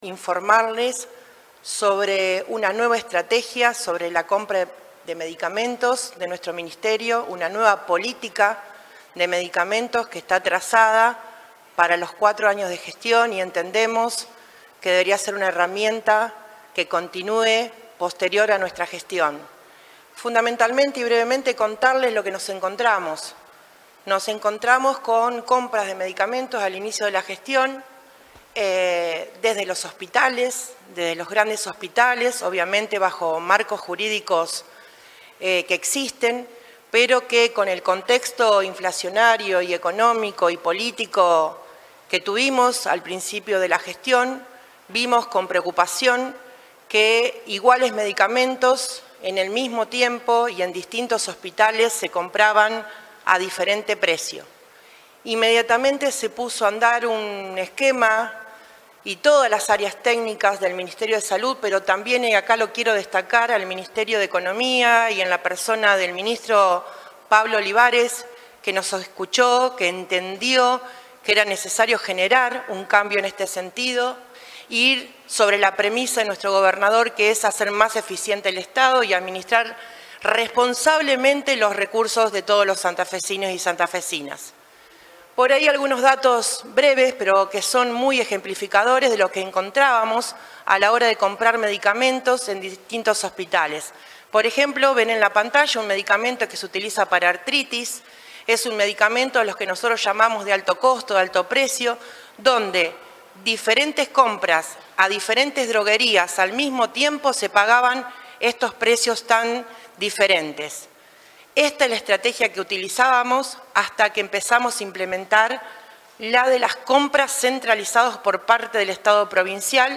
Así lo detallaron este martes durante una conferencia en la Casa de Gobierno de Santa Fe, la vicegobernadora, Gisela Scaglia, junto a la ministra de Salud, Silvia Ciancio y el secretario de Administración del ministerio de Salud, Guillermo Álvarez.